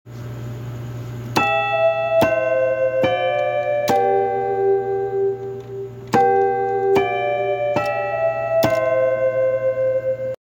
FNAF Ahh Horror Clock Sound Sound Effects Free Download